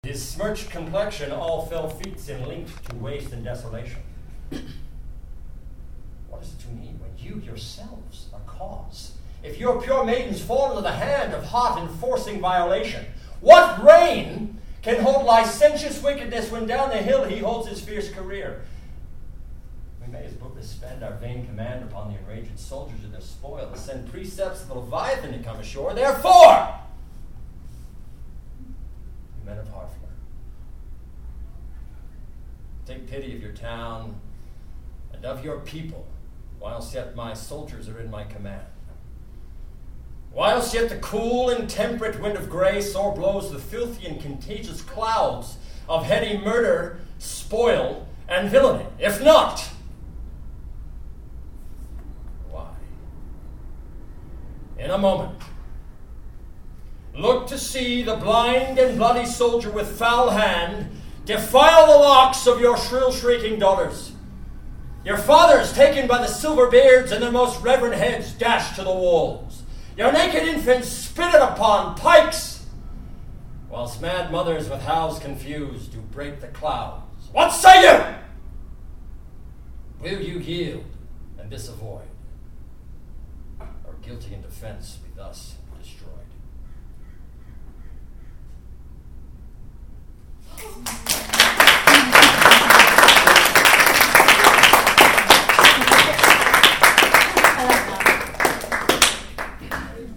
A BAFTA member performs Shakespeare's Henry V for students at George Washington Prep